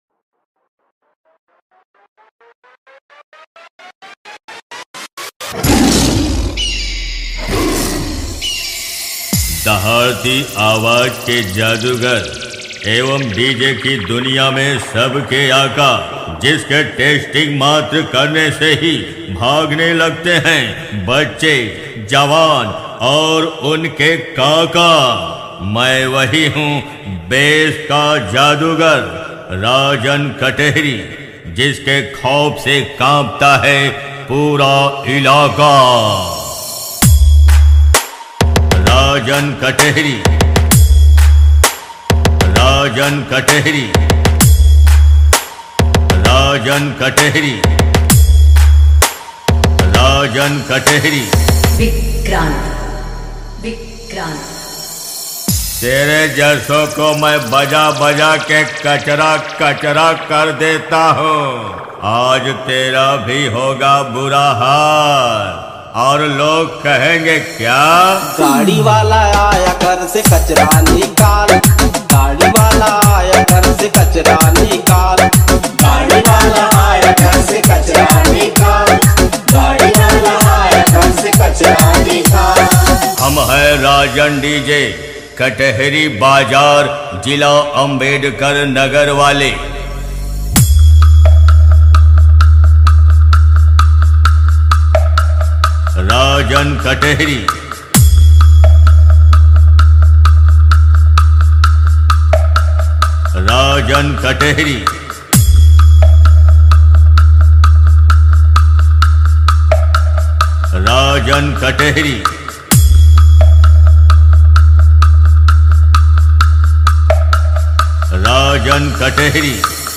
DJ Competition Songs
DJ Remix Songs